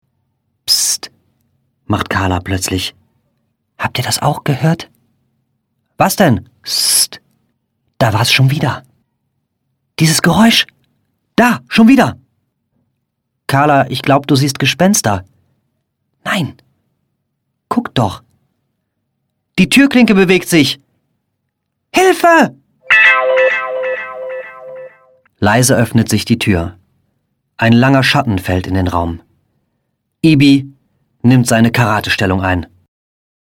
✔ tiptoi® Hörbuch ab 7 Jahren ✔ Jetzt online herunterladen!